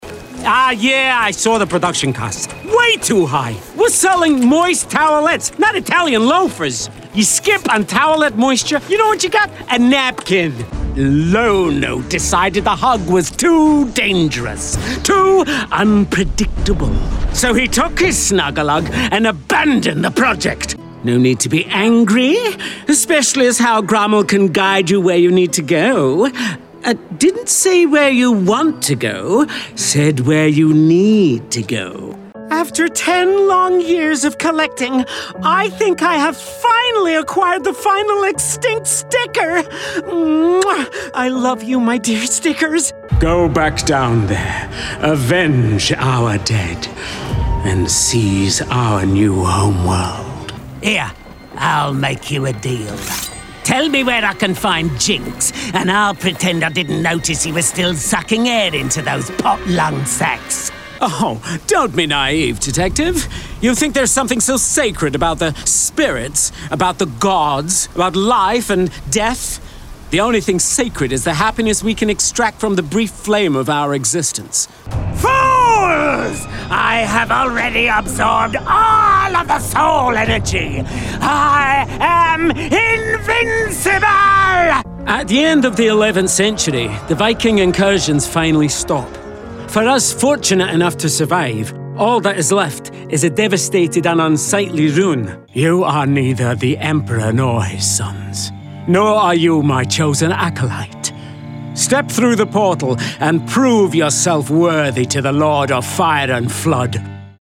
Commercial-Games-Demo.mp3